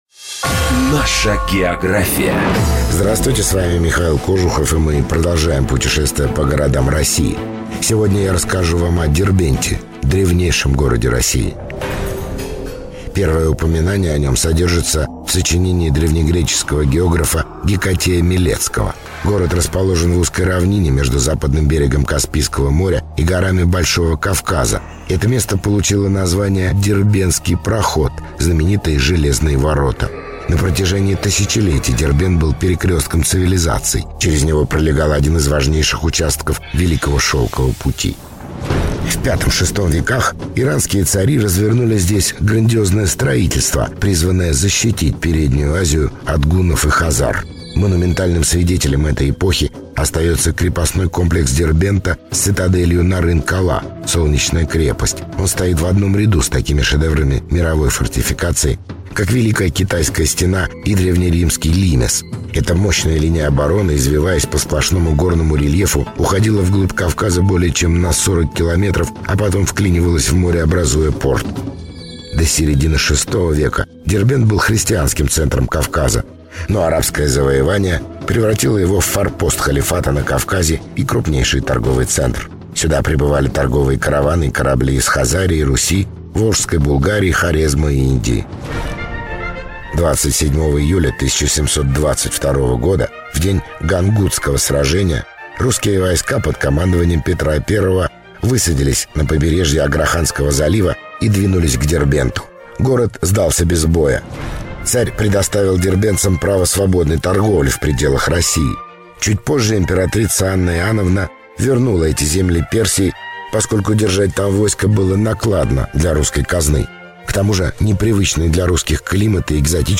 ведущий: Михаил Кожухов.